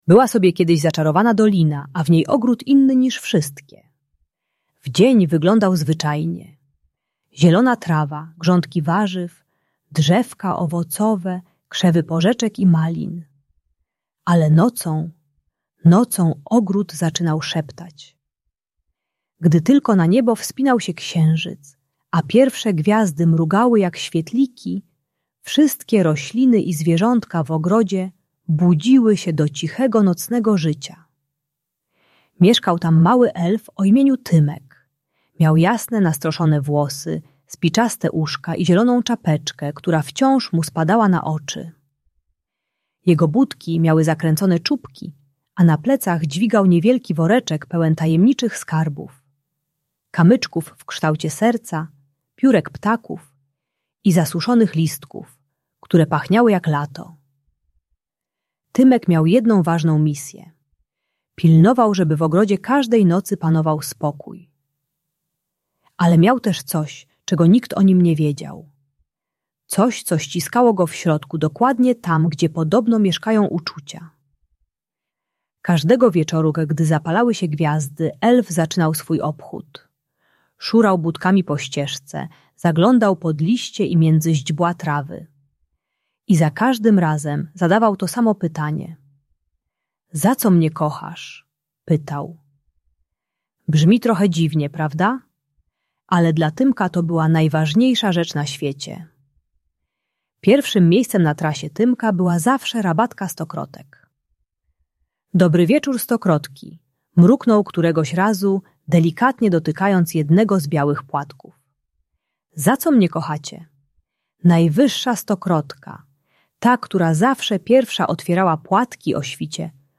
Zaczarowany ogród - Szkoła | Audiobajka